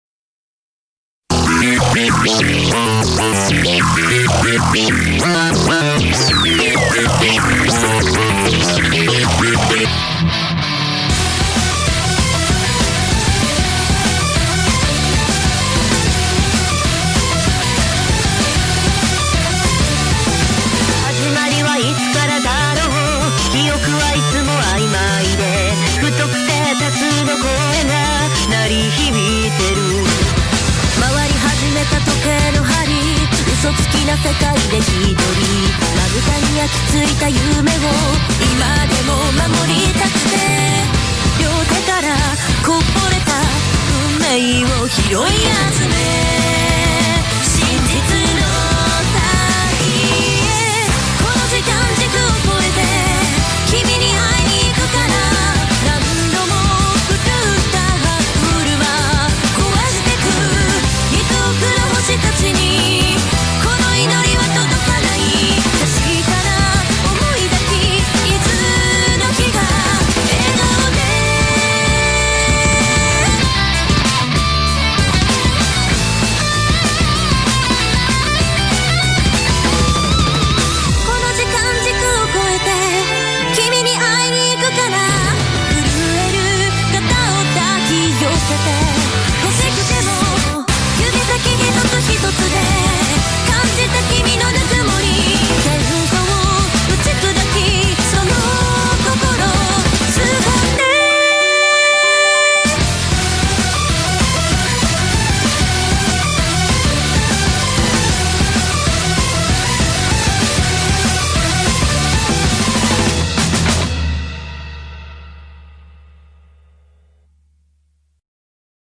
BPM193
Audio QualityPerfect (Low Quality)